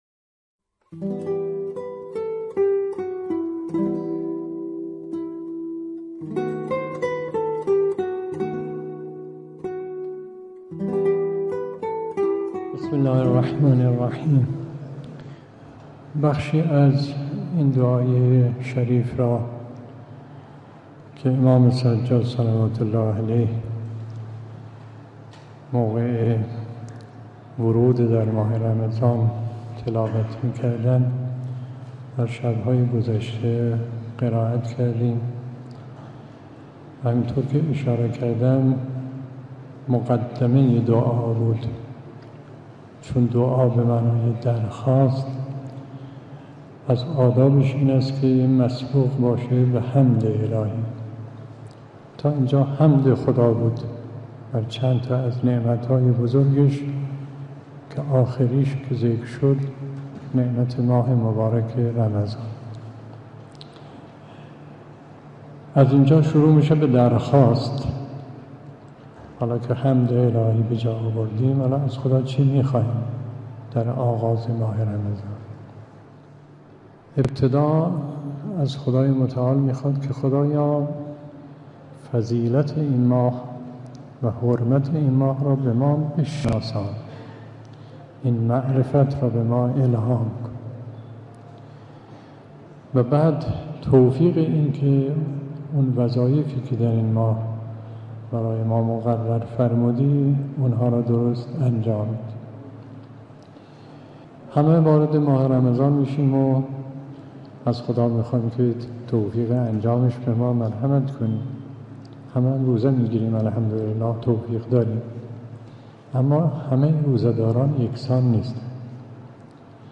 به گزارش پایگاه خبری 598، مرحوم آیت الله مصباح یزدی در یکی از دروس اخلاق به موضوع «فلسفه روزه داری» پرداخت که تقدیم شما فرهیختگان می شود.